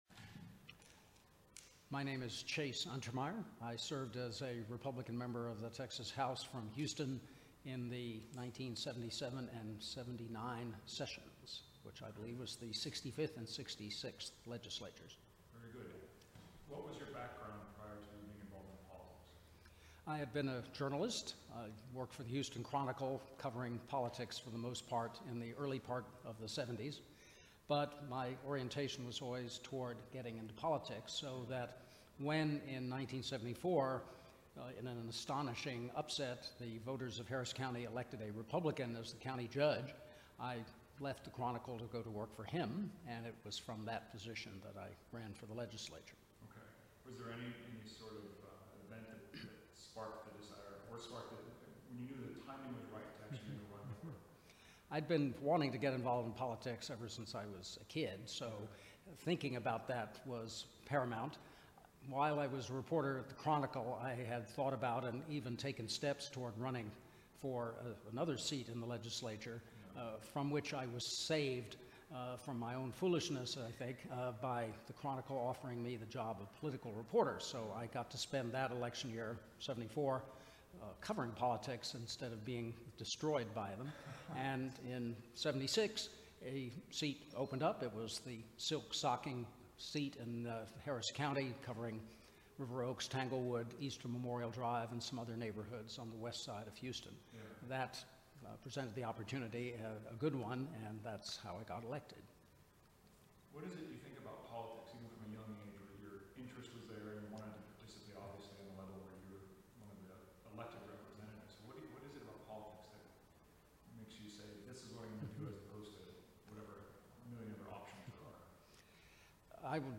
Oral history interview with Chase Untermeyer, 2015. Texas House of Representatives .